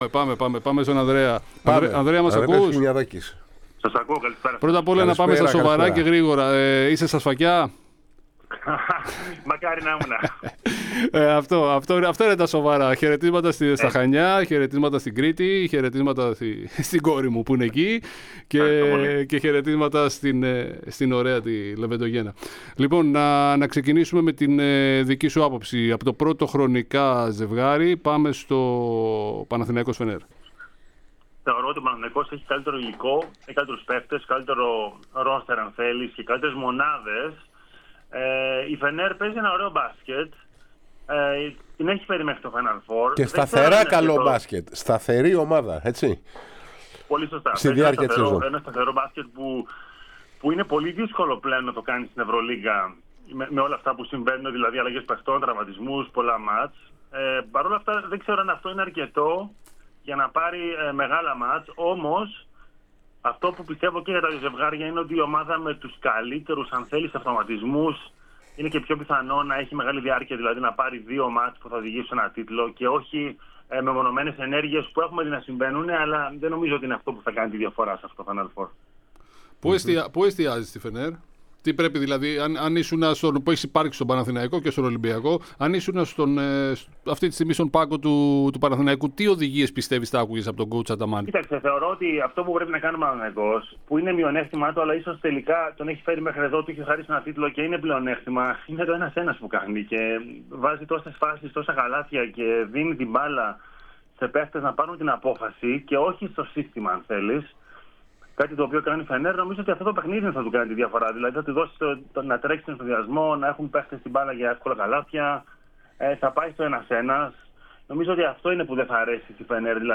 Ο πολύπειρος πρών παίκτης με θητείες και στους δύο "αιώνιους" κατέθεσε την άποψή του για τις αποστολές των ελληνικών ομάδων στο Final Four της EuroLeague, στην εκπομπή της ΕΡΑ ΣΠΟΡ "Άμπου Ντάμπι Ερχόμαστε".